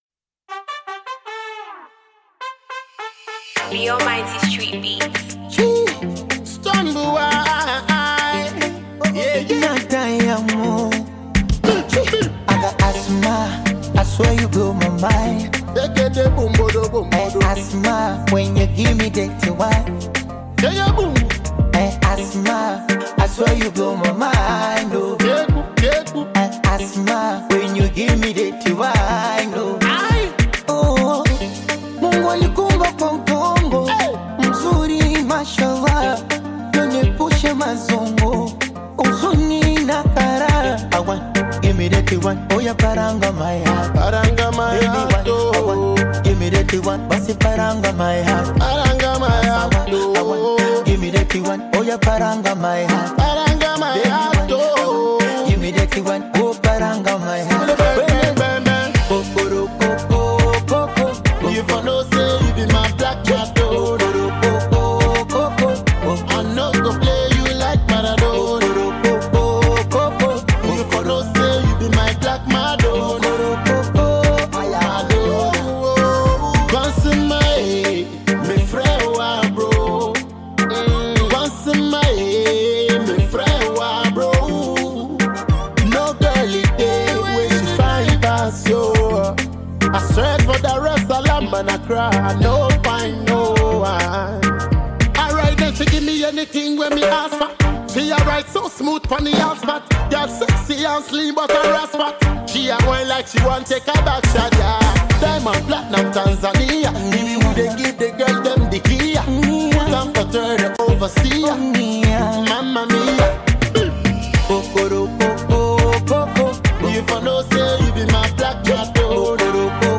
Multiple award-winning top notch dancehall singjay